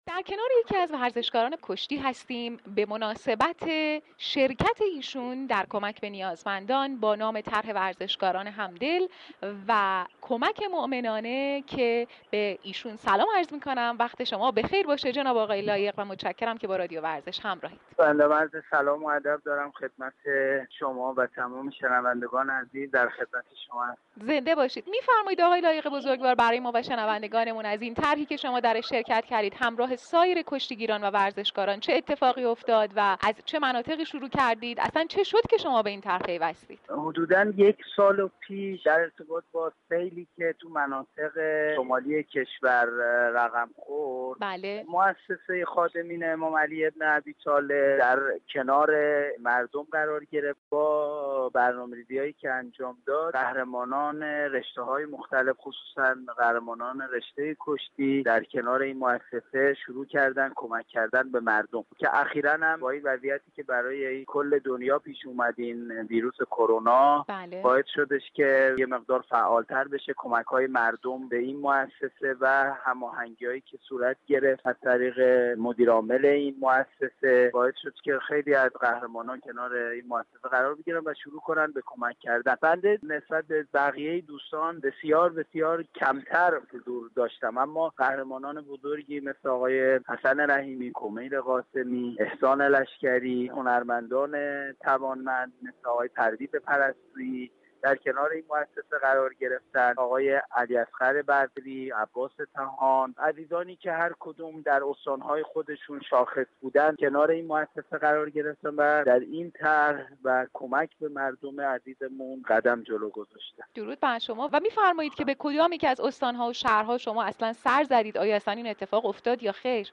شما می توانید از طریق فایل پیوست شنونده گفتگوی كامل رادیو ورزش با این كشتی گیر باشید.